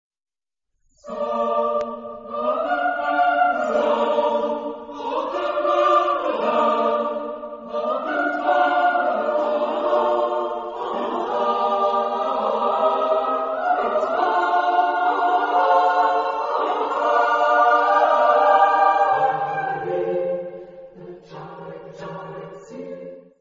Genre-Style-Forme : Profane ; Ode
Caractère de la pièce : joyeux ; majestueux
Type de choeur : SATB  (4 voix mixtes )
Tonalité : si bémol majeur